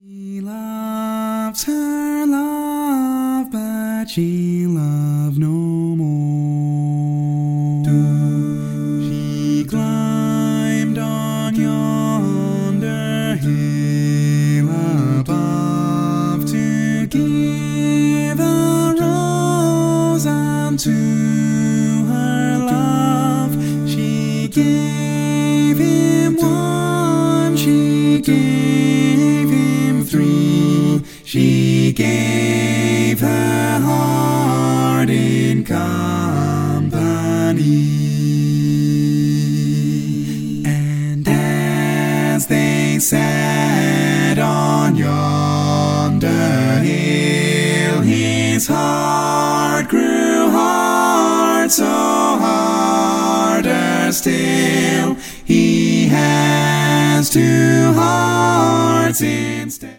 Full mix
Category: Male